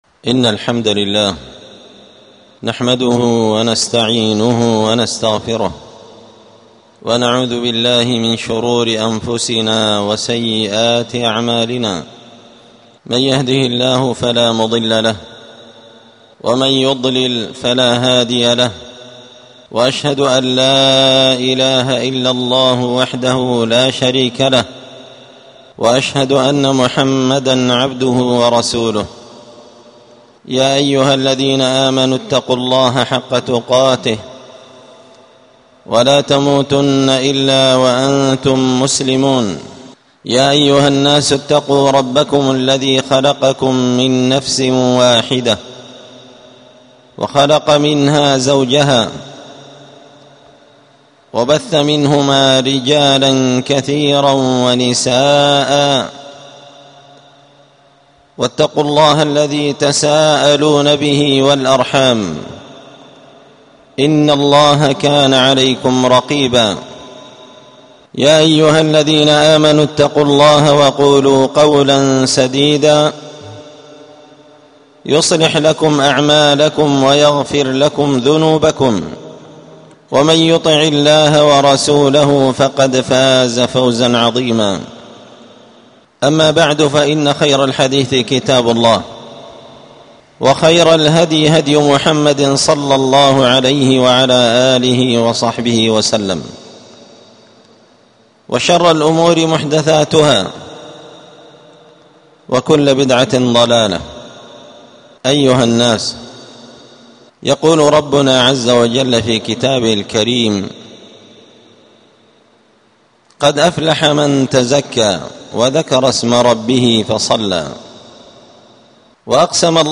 ألقيت هذه الخطبة بدار الحديث السلفية بمسجد الفرقان